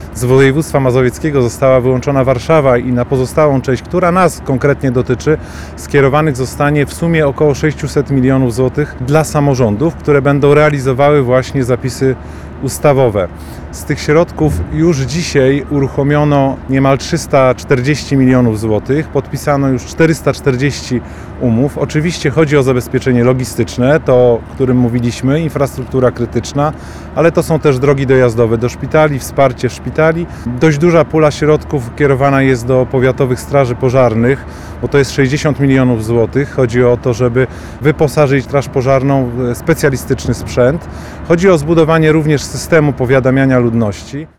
Konferencja przed płockim ratuszem
– mówił Adam Krzemiński, Poseł na Sejm RP.